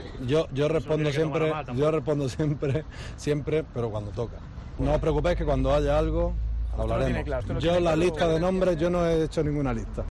AUDIO: El presidente de la RFEF emplazó a los periodistas a esperar a "cuando haya algo" para conocer más datos sobre el futuro seleccionador español.